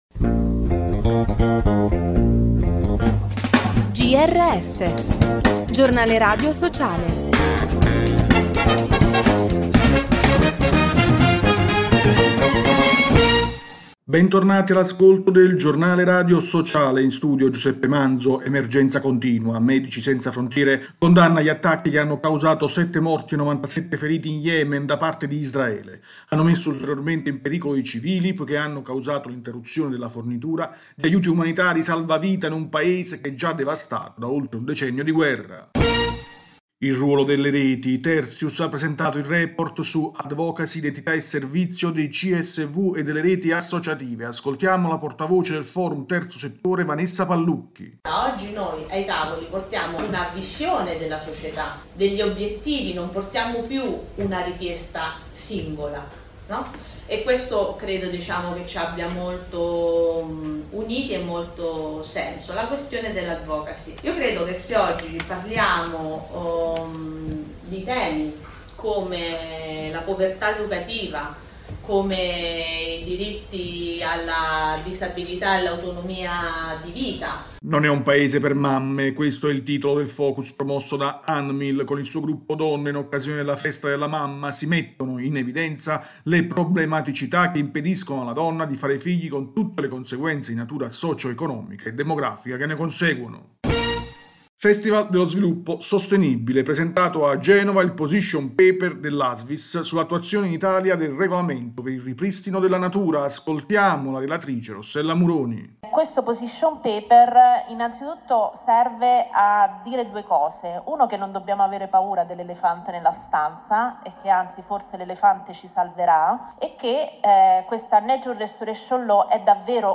Bentornati all’ascolto del Giornale radio sociale. In studio